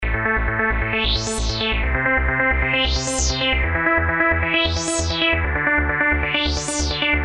描述：用reFX Vanguard制作的旋律。
标签： 高潮 舞蹈 DJ 标题 melodics 旋律 跟踪 精神恍惚 精神恍惚门 捻线机
声道立体声